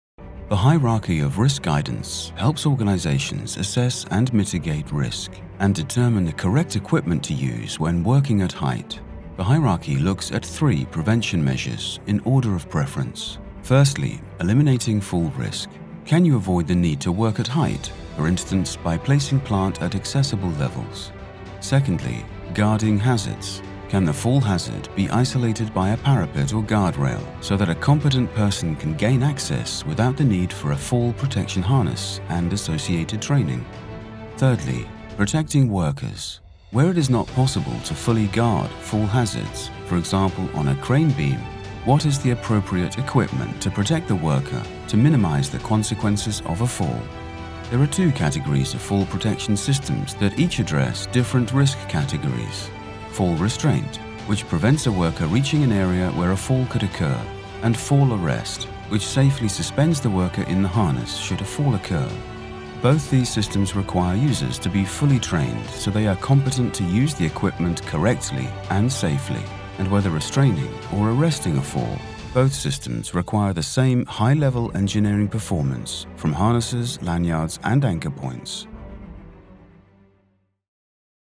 Anglais (Britannique)
Profonde, Naturelle, Fiable, Amicale, Chaude
E-learning